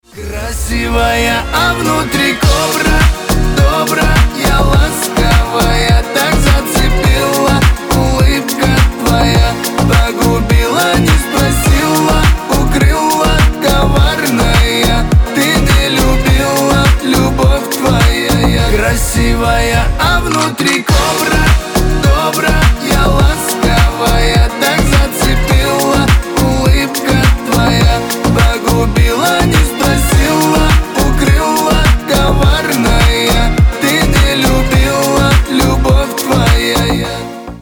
• Песня: Рингтон, нарезка
• Категория: Русские рингтоны